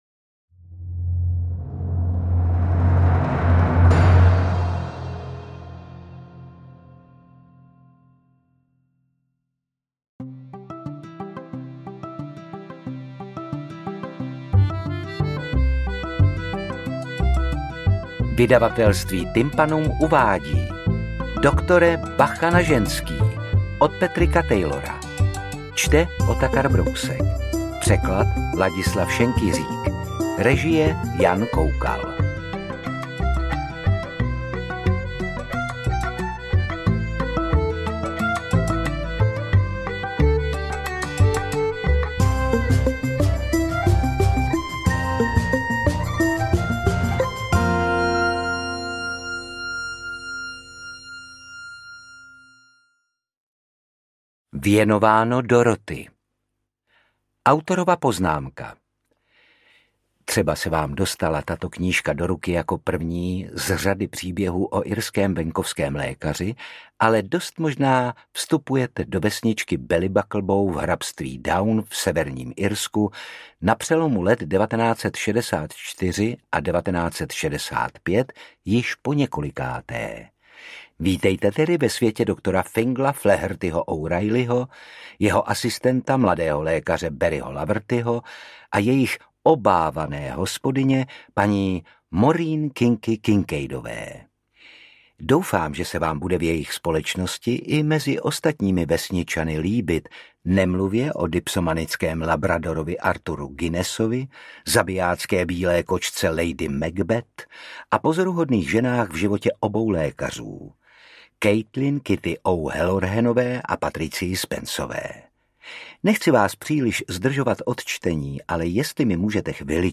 Interpret:  Otakar Brousek
AudioKniha ke stažení, 52 x mp3, délka 16 hod. 55 min., velikost 930,7 MB, česky